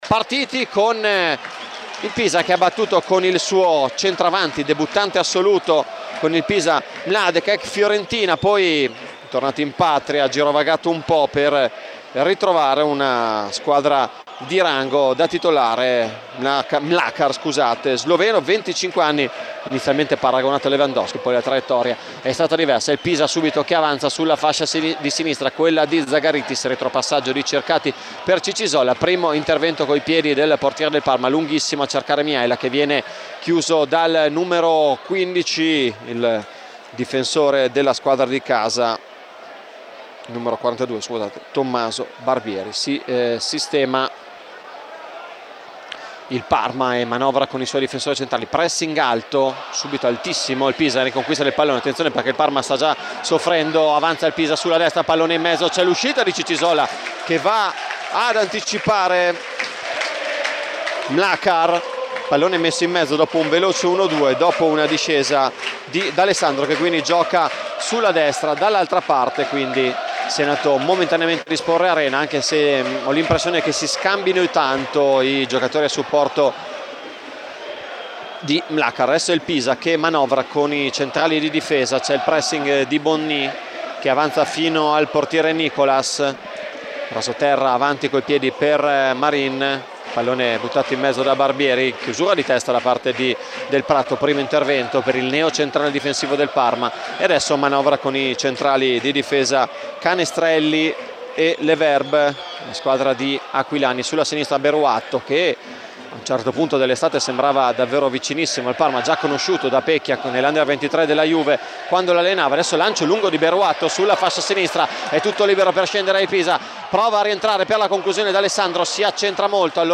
Radiocronache Parma Calcio Pisa - Parma 1° tempo - 29 agosto 2023 Aug 29 2023 | 00:48:03 1x 00:00 / 00:48:03 Subscribe Share RSS Feed Share Link Embed